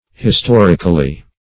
Historically \His*tor"ic*al*ly\, adv.